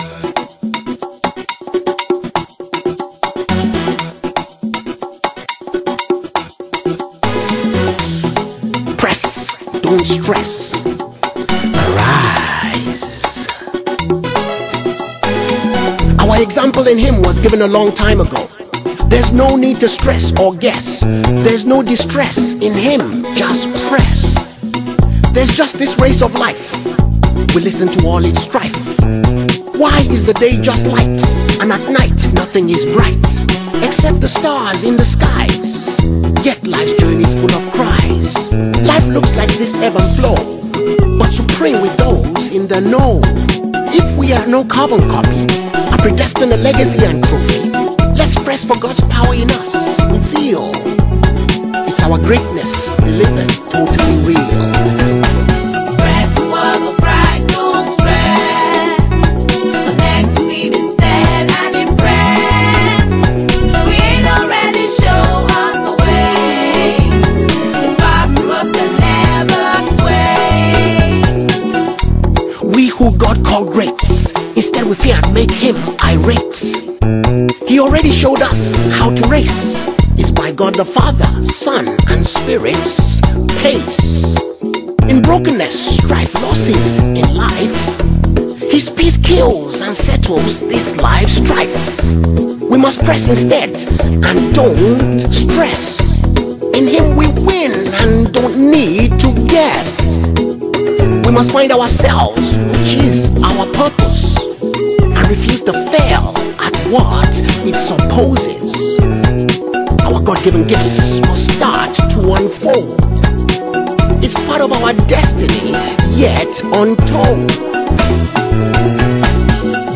poetically imbued and rhythmic song